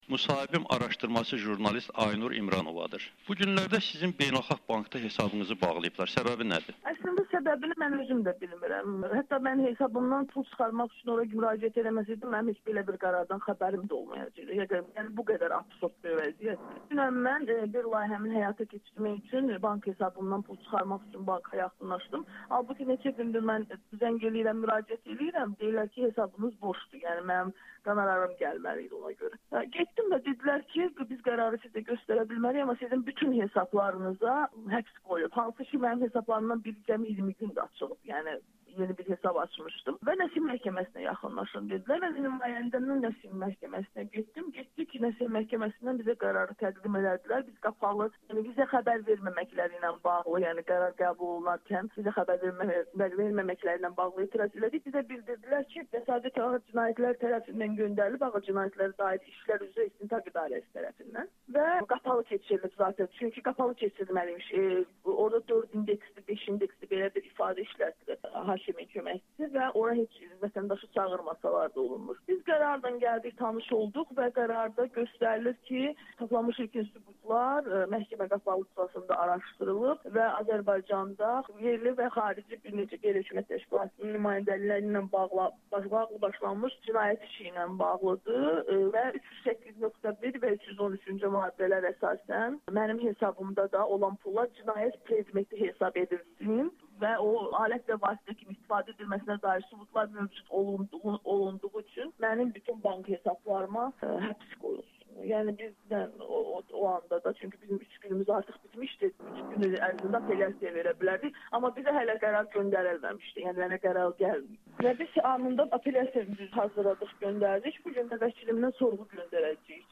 Xarici və yerli QHT-lərə qarşı cinayət işi - jurnalistin bank hesabına həbs qoyulub [Audio-müsahibə]